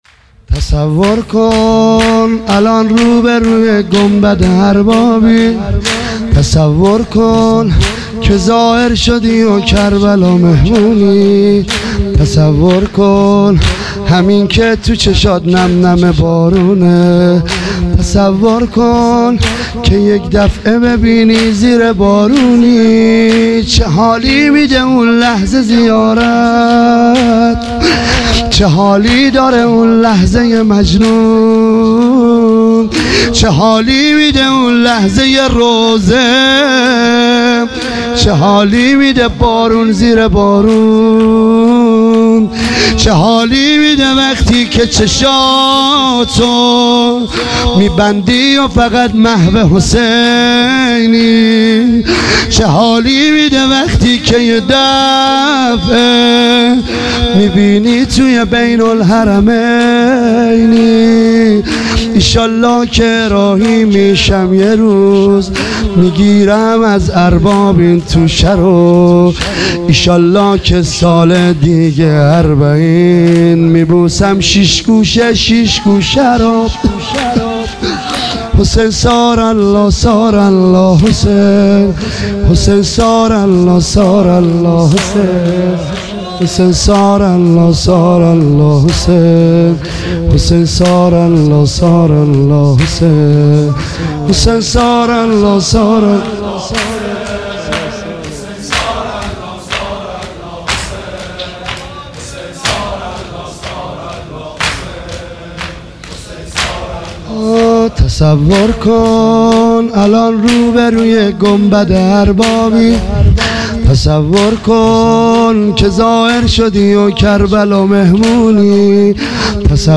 چهارضرب